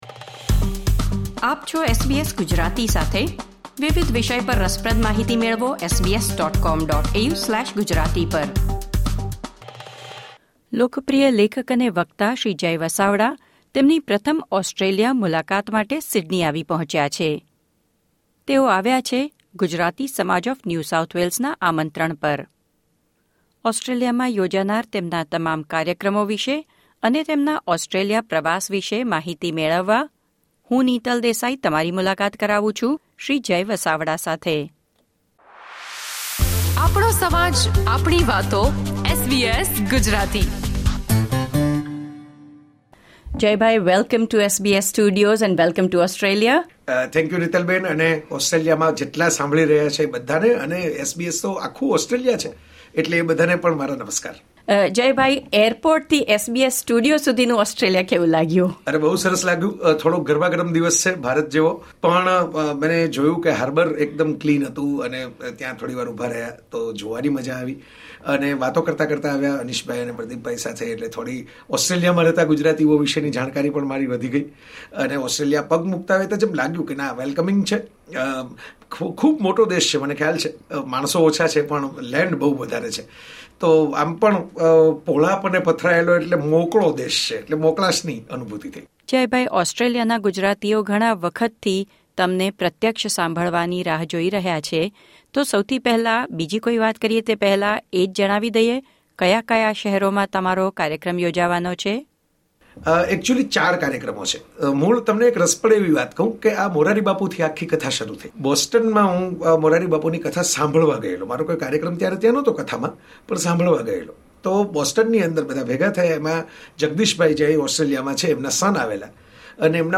સિડની સ્થિત SBS સ્ટુડિયોની તેમણે મુલાકાત દરમિયાન તેમણે દેશના ચાર શહેરો કેનબેરા, સિડની, મેલ્બર્ન અને બ્રિસબેનમાં કાર્યક્રમ વિશે વાત કરી હતી.
Famous Gujarati author and speaker Jay Vasavada visited SBS studio in Sydney.